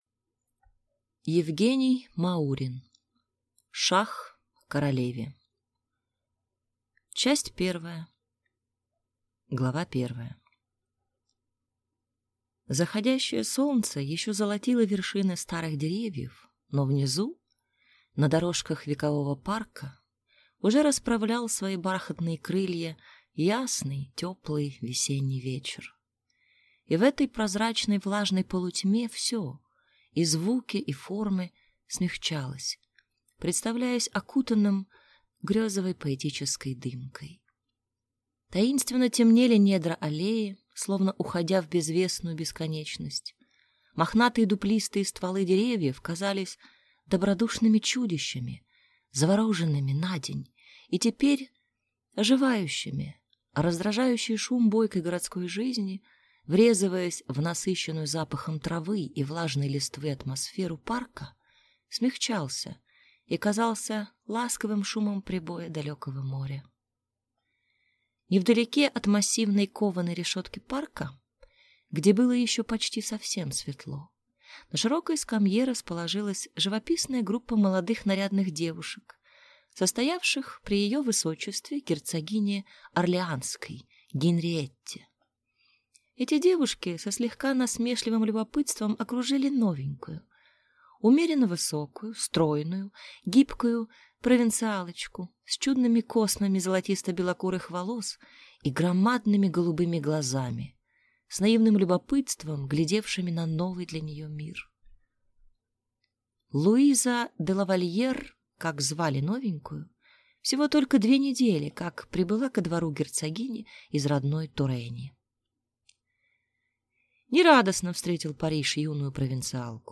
Аудиокнига Шах королеве | Библиотека аудиокниг